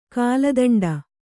♪ kāladaṇḍa